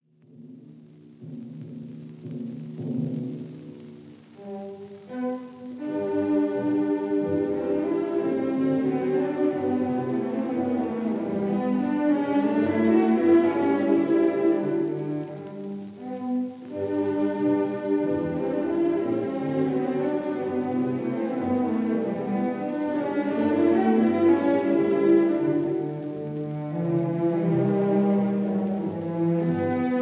Allegro ma non troppo